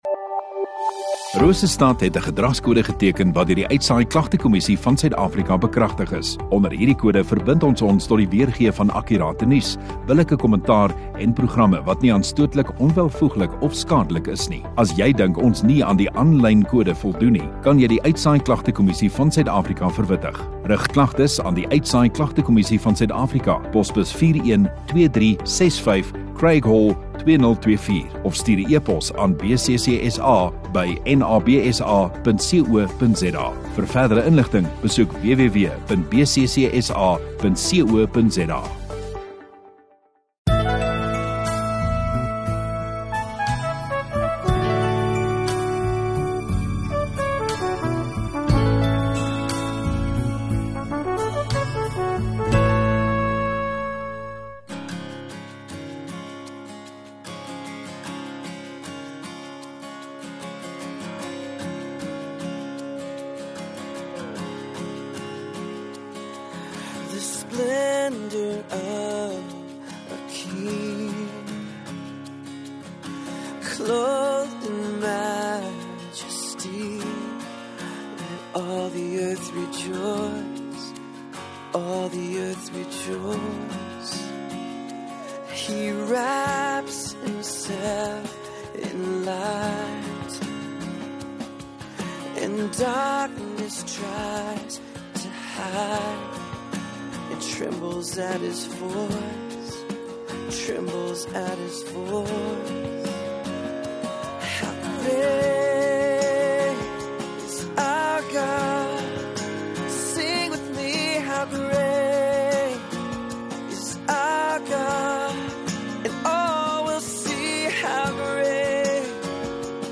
7 Jan Dinsdag Oggenddiens